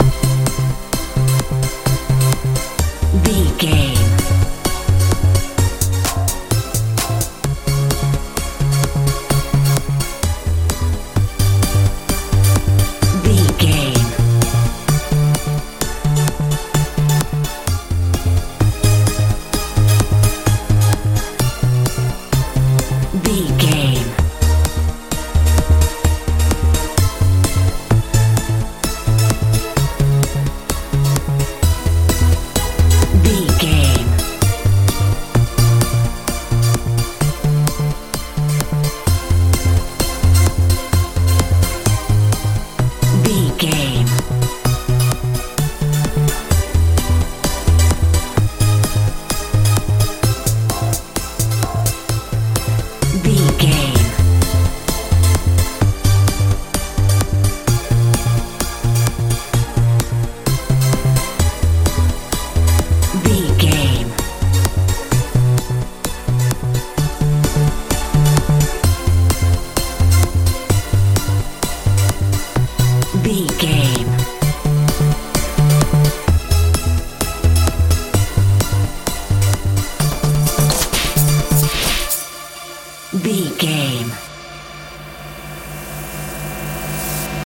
euro dance
Ionian/Major
fun
playful
synthesiser
bass guitar
drums
uplifting
futuristic